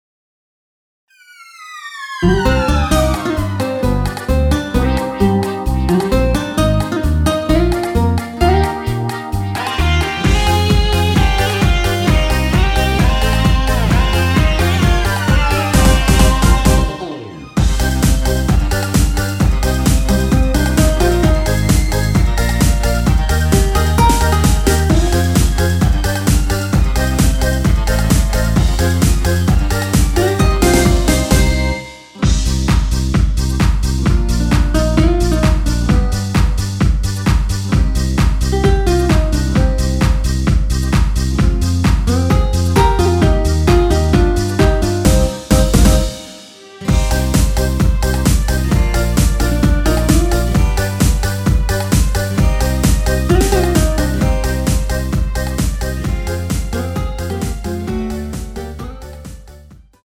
원키에서(-1)내린 MR입니다.
Cm
앞부분30초, 뒷부분30초씩 편집해서 올려 드리고 있습니다.
중간에 음이 끈어지고 다시 나오는 이유는